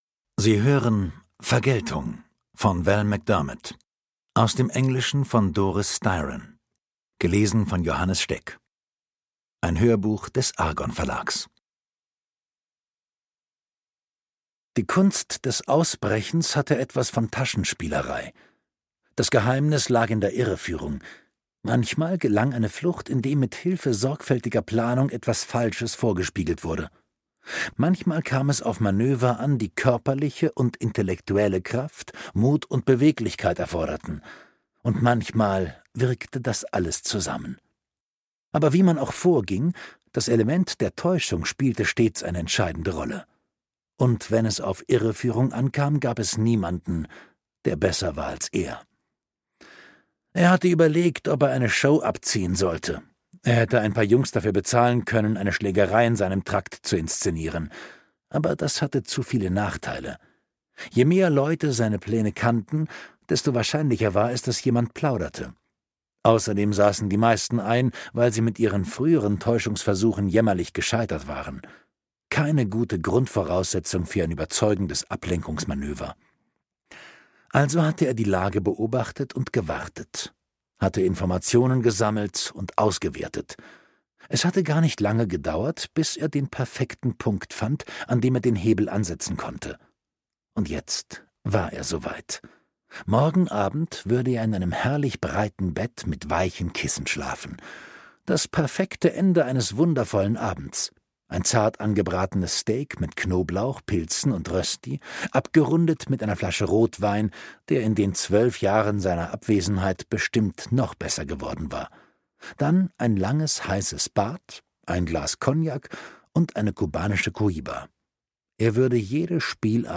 Schlagworte Carol Jordan • Ermittlerteam • Hörbuch; Krimis/Thriller-Lesung • Profiler • Psychopath • Rache • Tony Hill